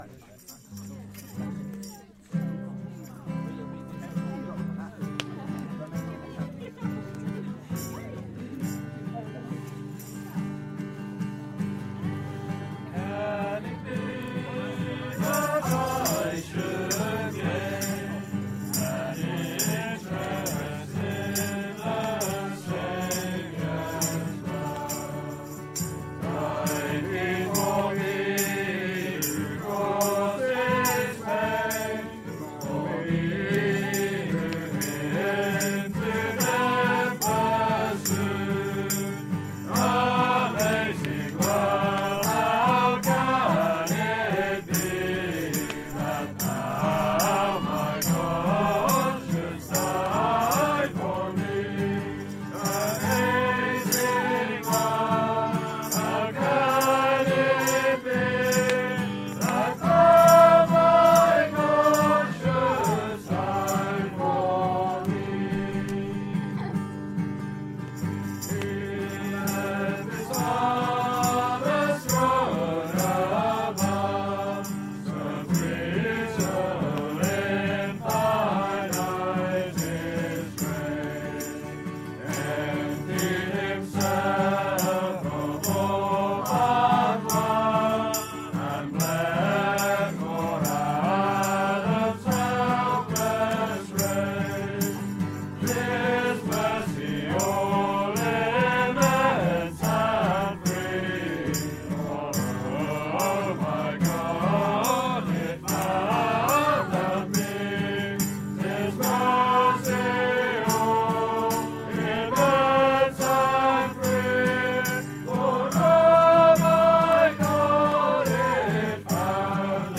Listen to a collection of Hymns & Songs from Ignite 2018 sung around a campfire.
Listen to a collection of Hymns by the Campfire recorded from Ignite 2018.